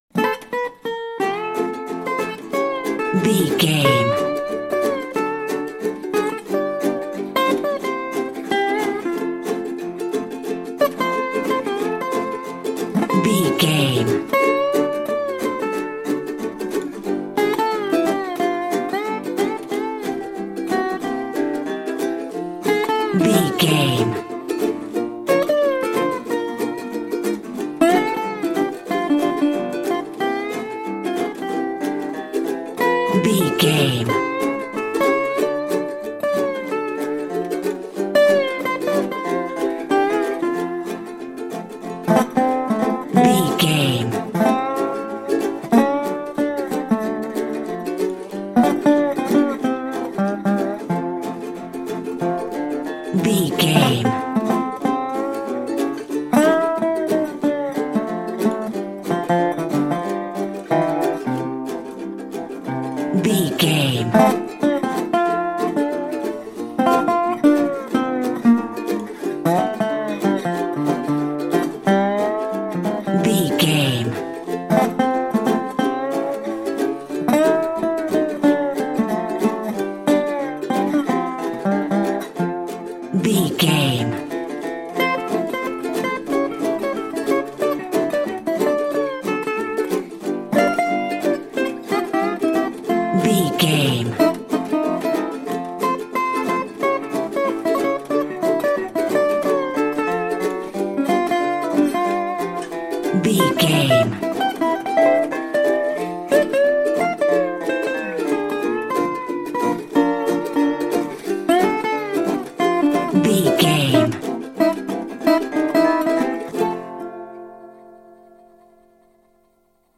Ionian/Major
acoustic guitar
ukulele
dobro
slack key guitar